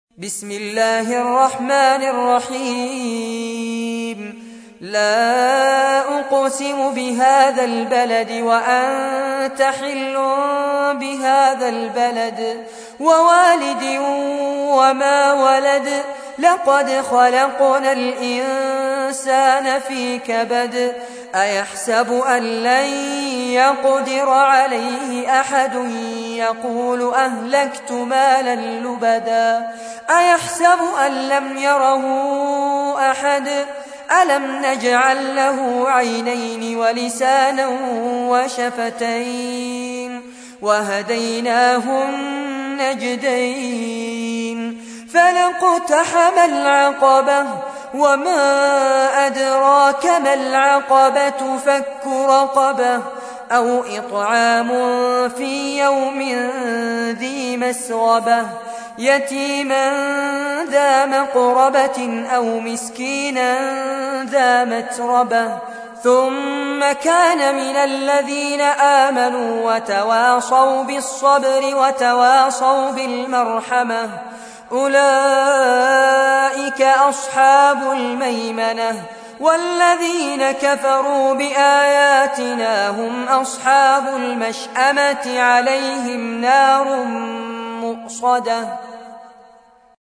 تحميل : 90. سورة البلد / القارئ فارس عباد / القرآن الكريم / موقع يا حسين